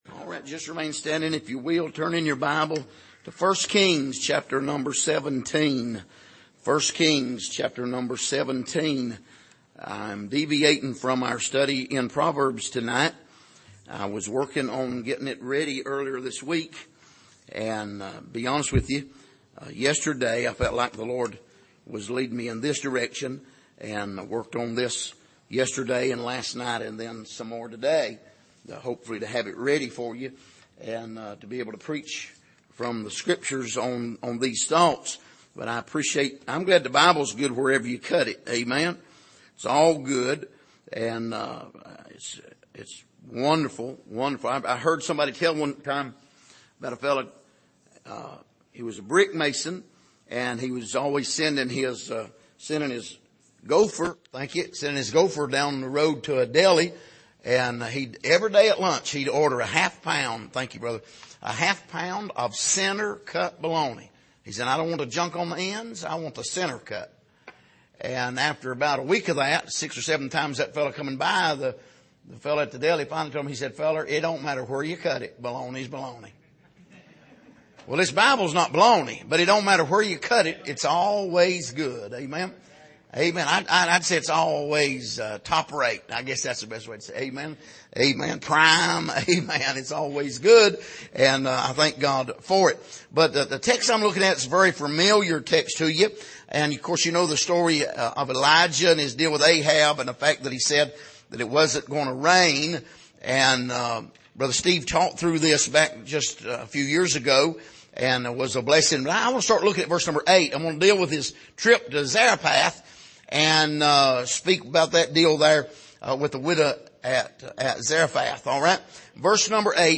Passage: 1 Kings 17:8-16 Service: Sunday Evening